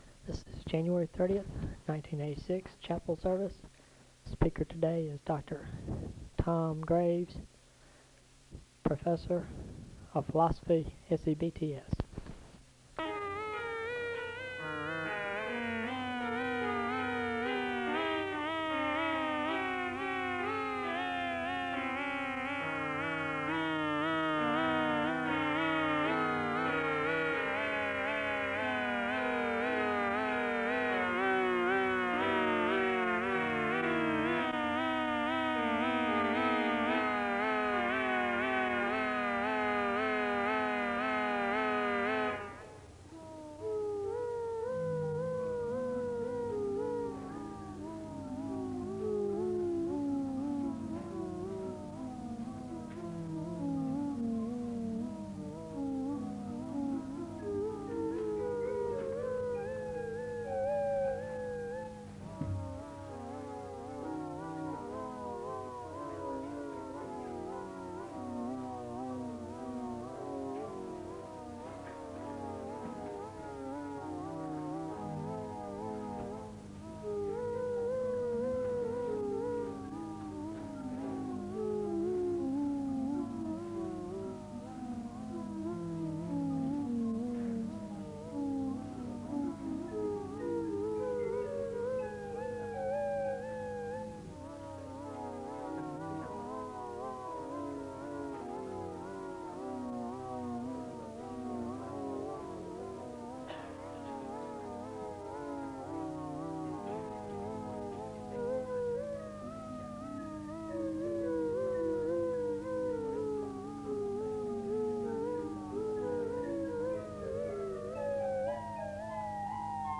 The service begins with organ music (0:00-4:35).
There is a call to worship from Isaiah 40:28-31 and Psalms 13 (4:36-6:25). There is a moment of prayer (6:26-7:27). A woman sings a song of worship (7:28-12:13).
Location Wake Forest (N.C.)